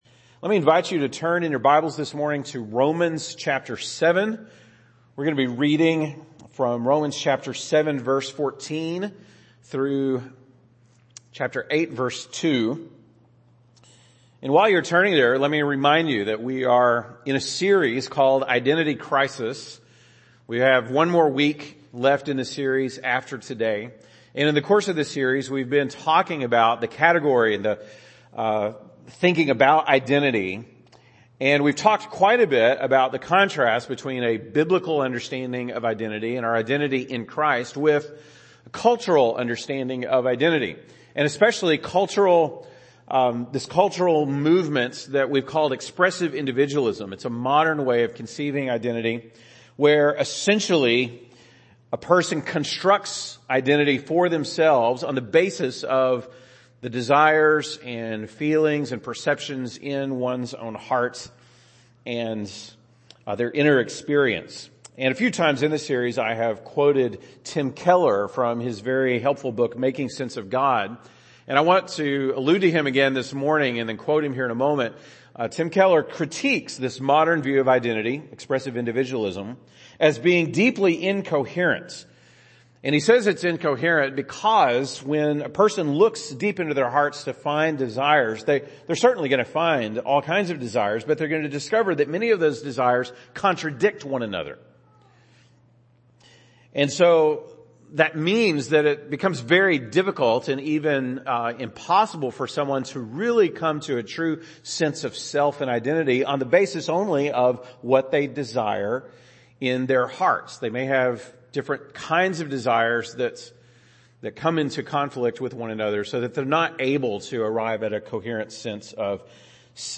( Sunday Morning )